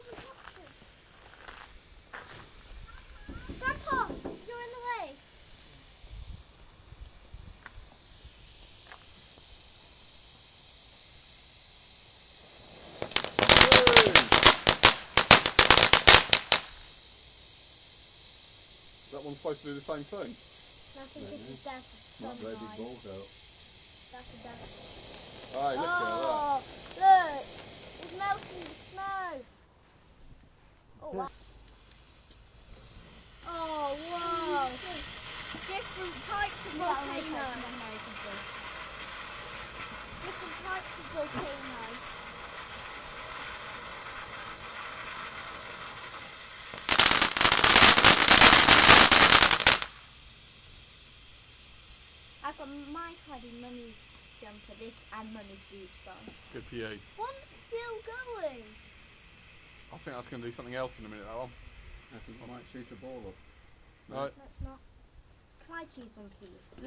Christmas Fireworks
86785-christmas-fireworks.mp3